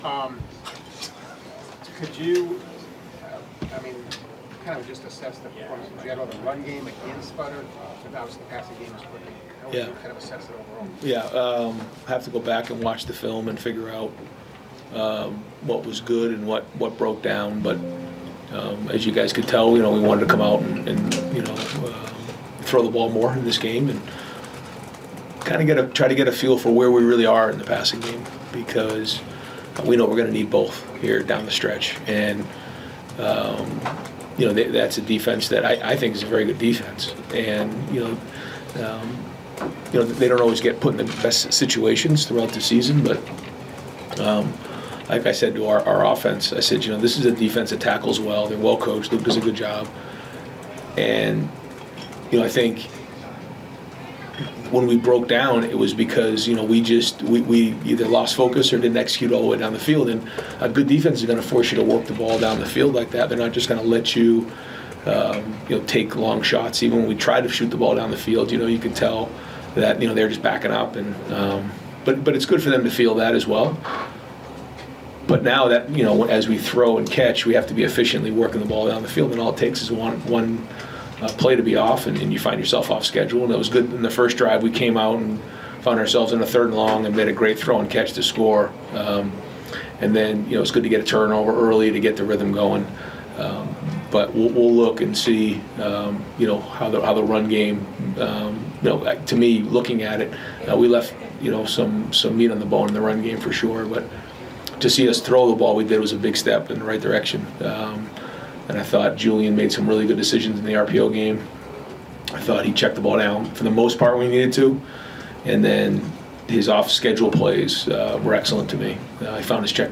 #1 Buckeyes get to second bye week with 7-0 record after dismantling outmanned Wisconsin, 34-0; Ryan Day Postgame Press Conference